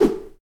whoosh.ogg